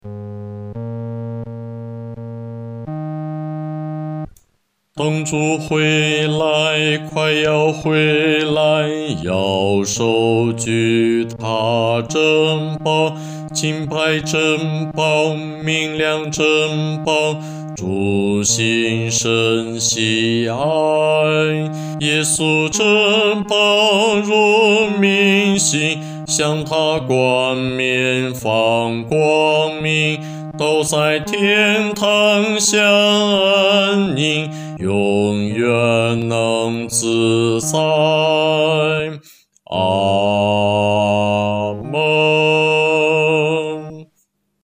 独唱（男低）